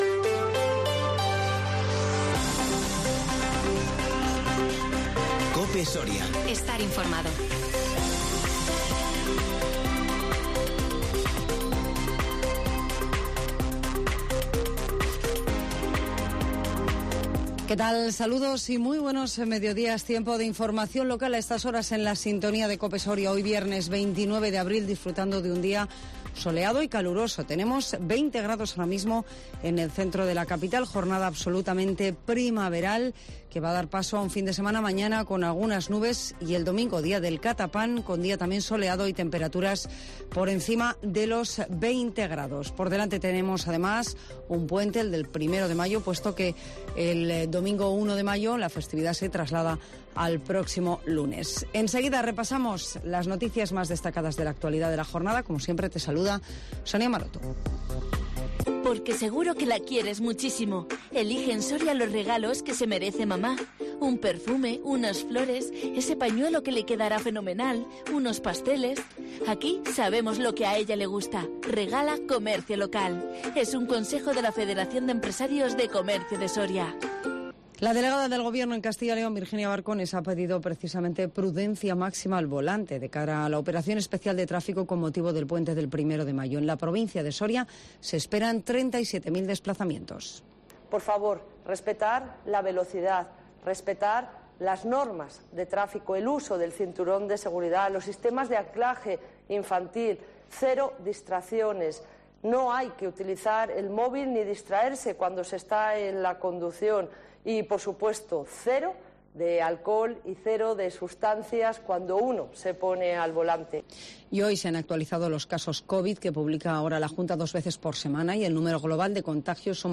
INFORMATIVO MEDIODÍA COPE SORIA 29 ABRIL 2022